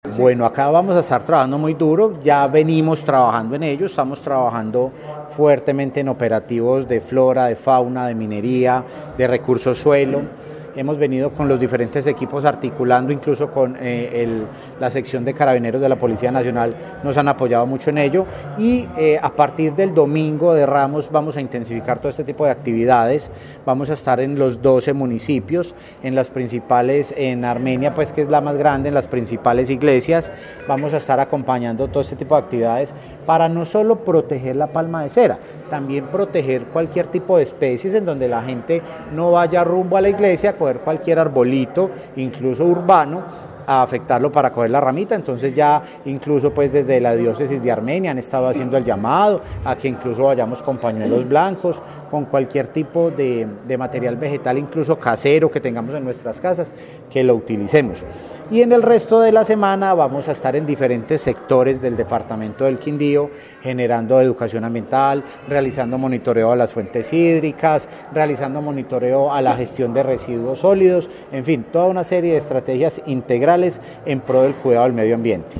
Director encargado de la CRQ